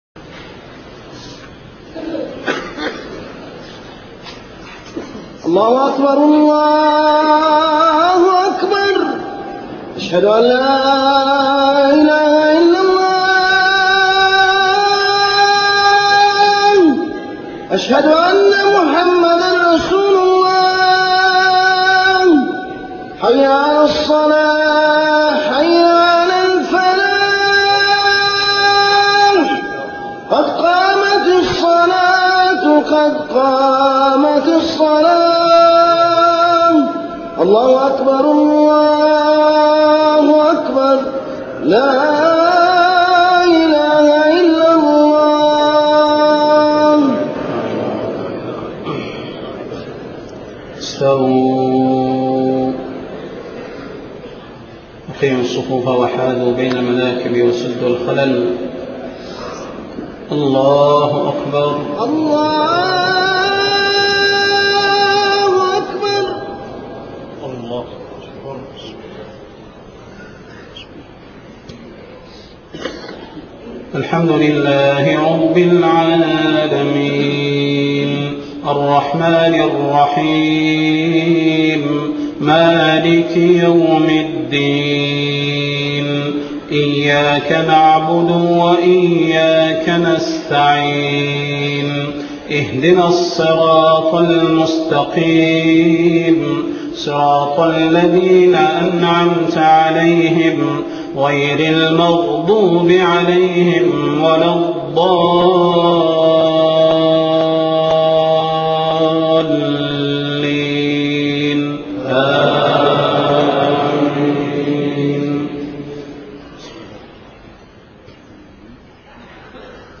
صلاة الجمعة 5 محرم 1430هـ من سورة الأنفال 36-40 > 1430 🕌 > الفروض - تلاوات الحرمين